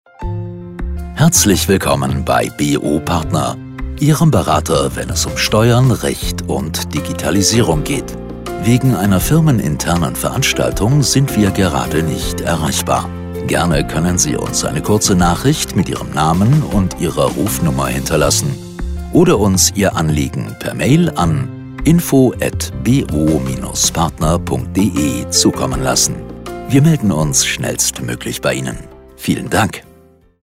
Telefonansage Steuern – Recht – Vermögen
Ansage Firmenevent